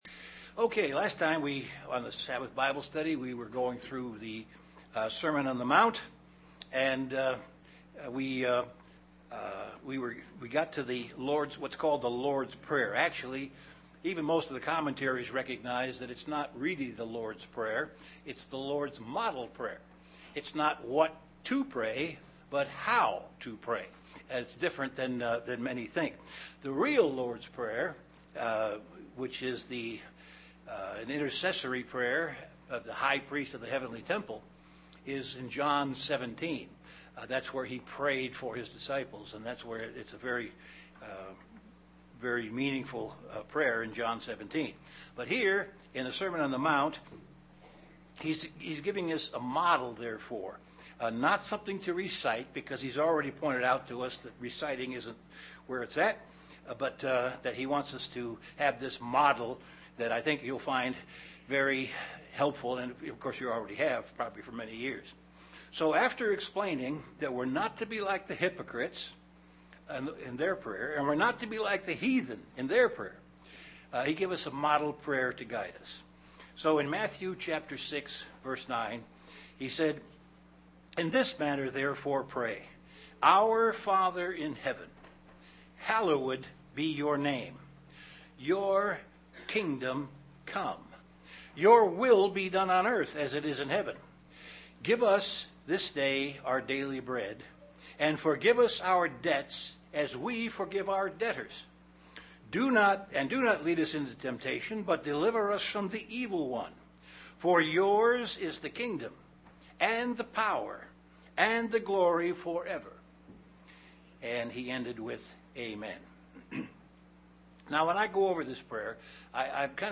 UCG Sermon Sermon on the Mount Studying the bible?